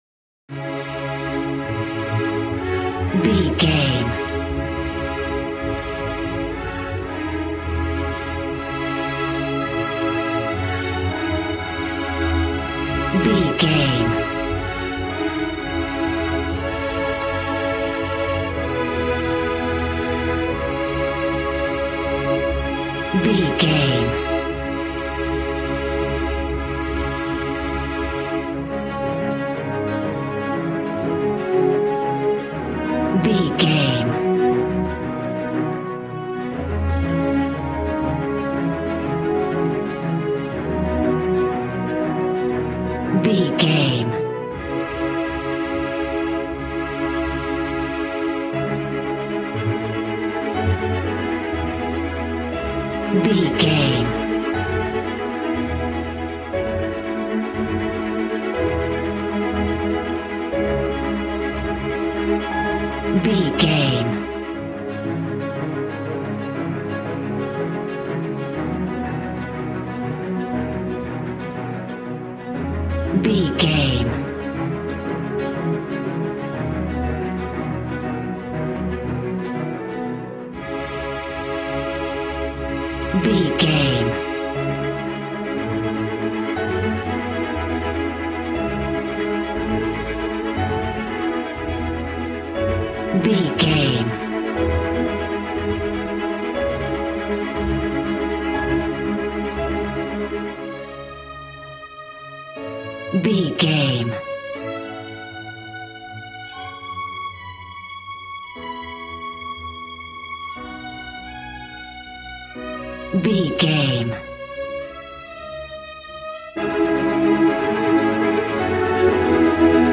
Regal and romantic, a classy piece of classical music.
Ionian/Major
regal
cello
violin
strings